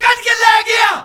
All Punjabi Vocal Pack